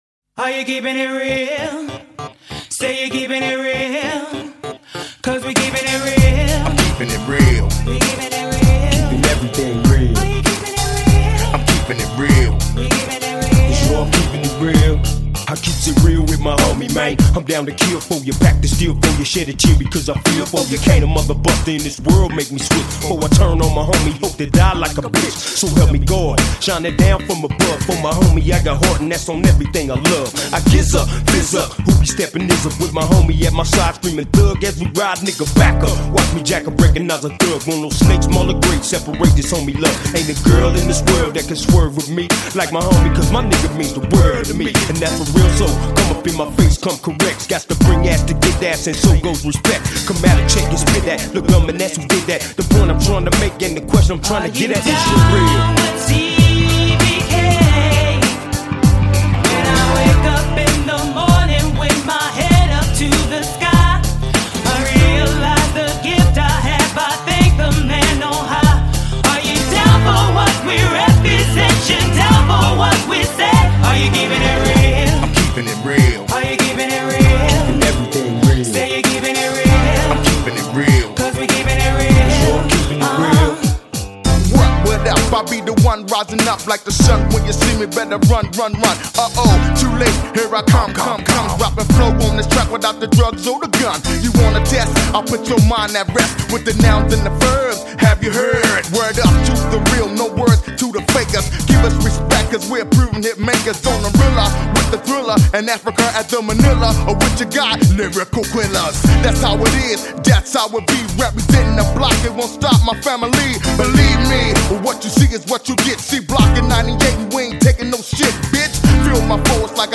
Жанр: Rap, Hip Hop